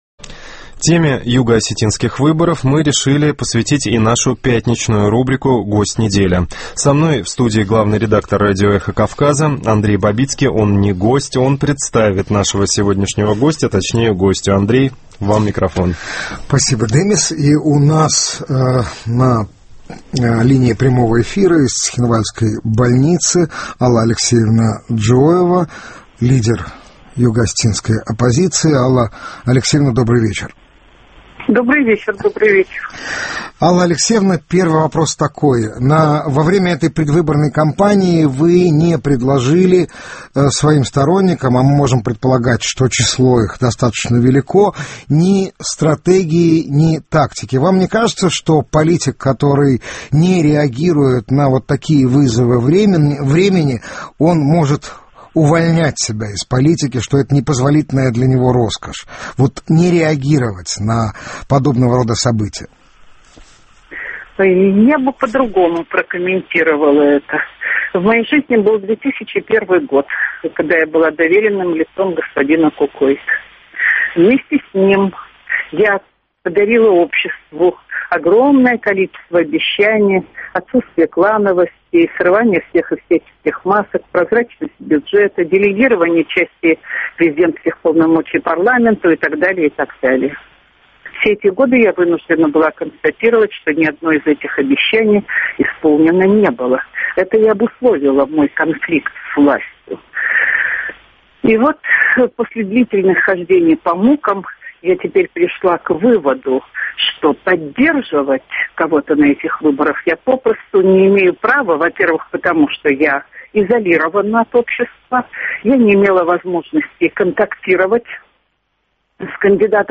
У нас на линии прямого эфира из цхинвальской больницы Алла Алексеевна Джиоева.